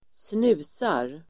Uttal: [²sn'u:sar]